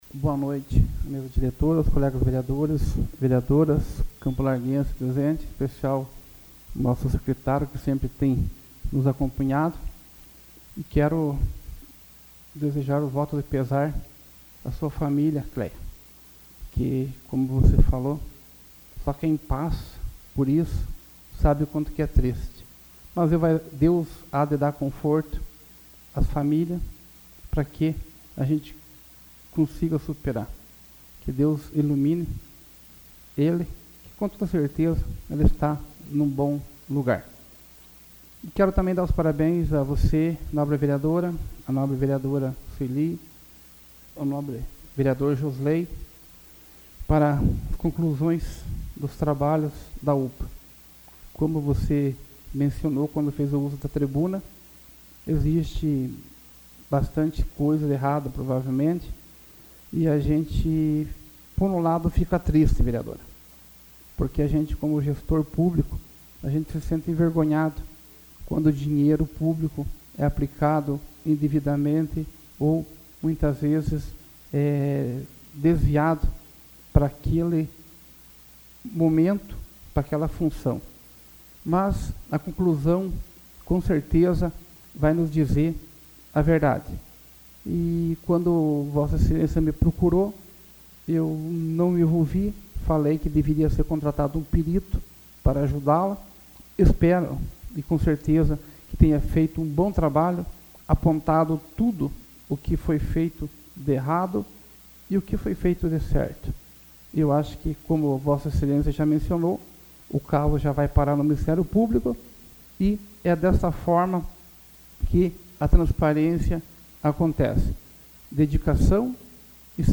Explicação pessoal AVULSO 25/02/2014 Dirceu Mocelin